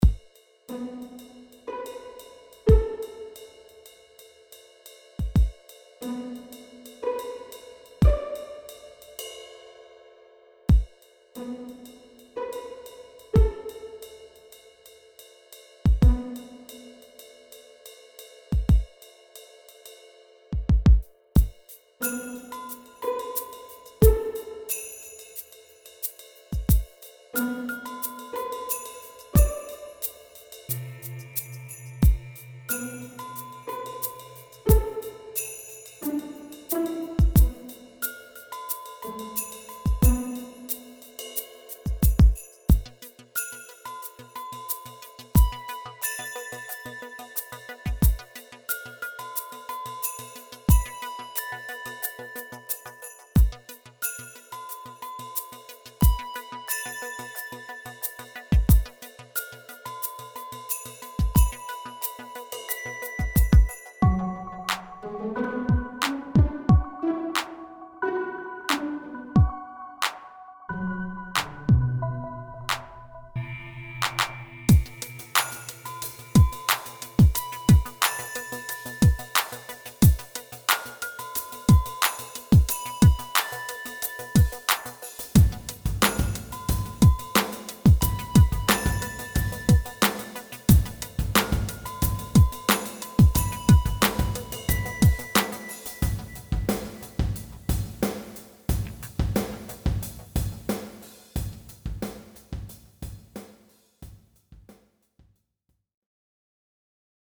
Genre: Hip Hop Pizzicato Tension.